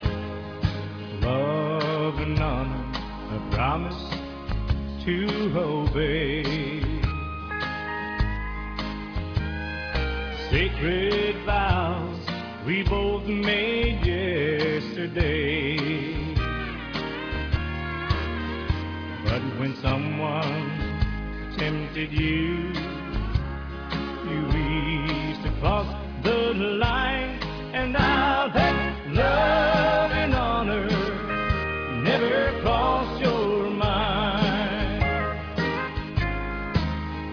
OF COUNTRY MUSIC TALENT.
A SMOOTH, 'SOUL RENDERING' VERSION OF ANY SONG HE CHOOSES